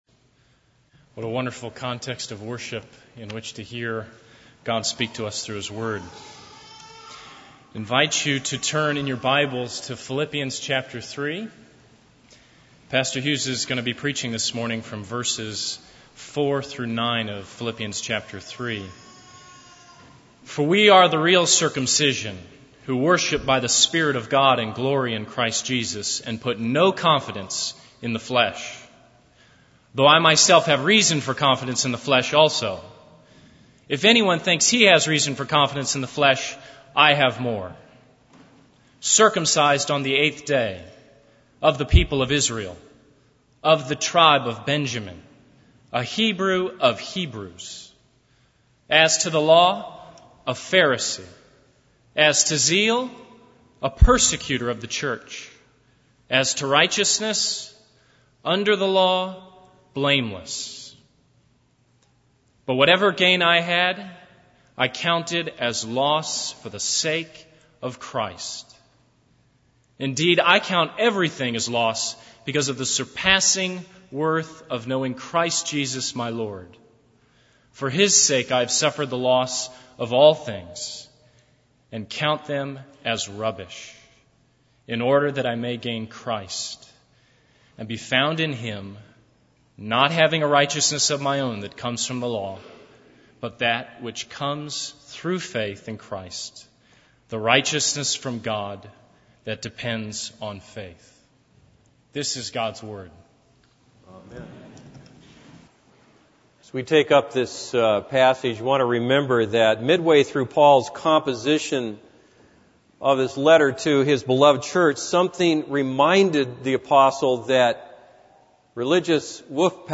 This is a sermon on Philippians 3:4-9.